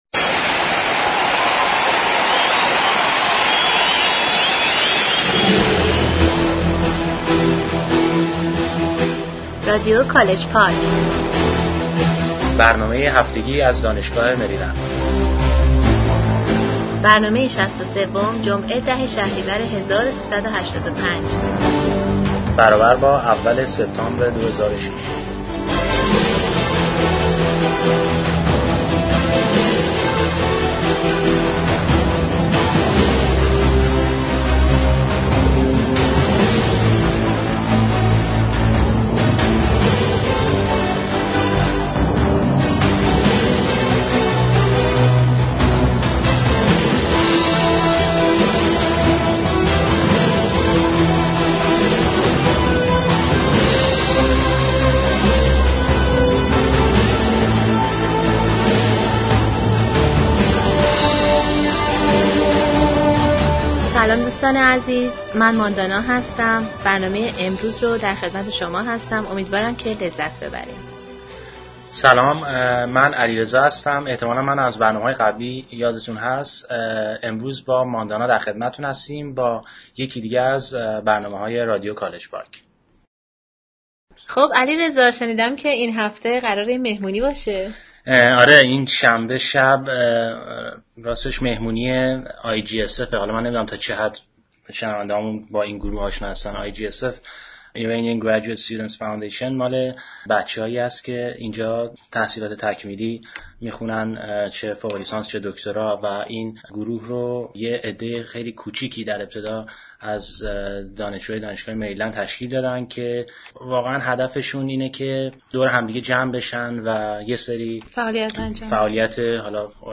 A Round Table on Emigration (Part 1)